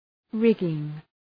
Προφορά
{‘rıgıŋ}